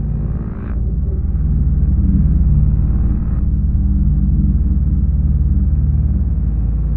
core_contained_loop1.wav